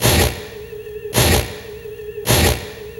80BPM RAD6-L.wav